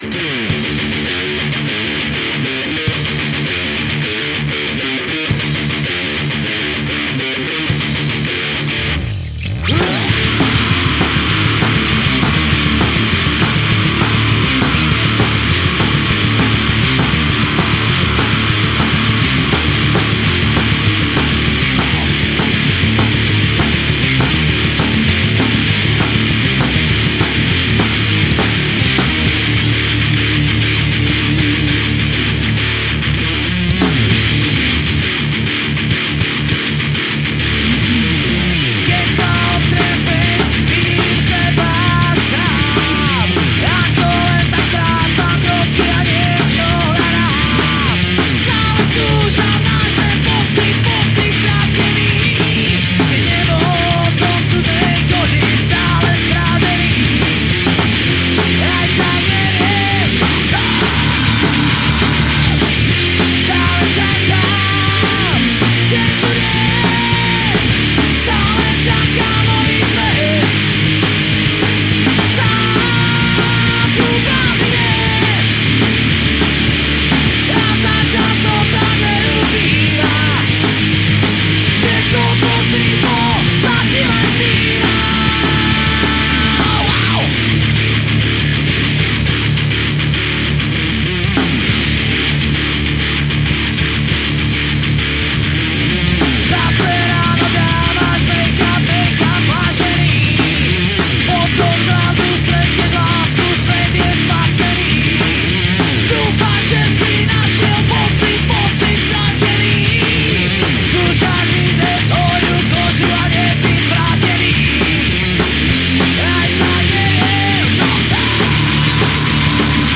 Žánr: Rock
Vocals, guitars
Bass guitar
Drums